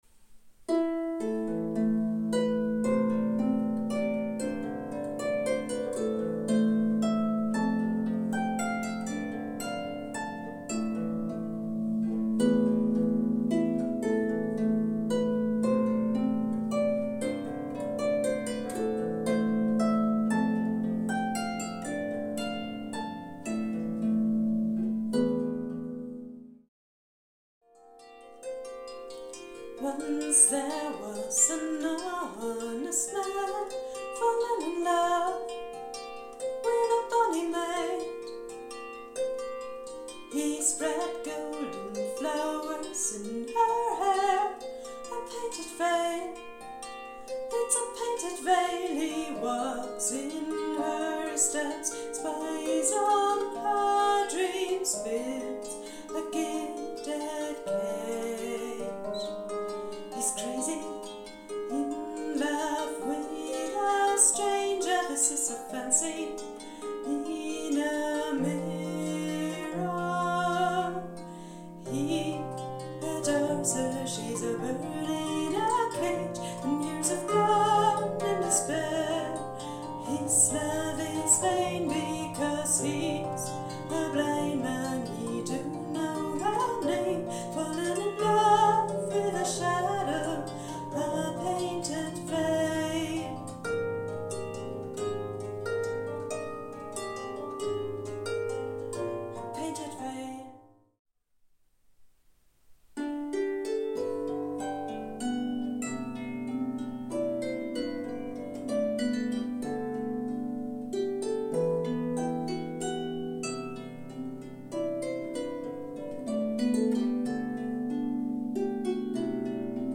Gewölbekonzert
Harfnerin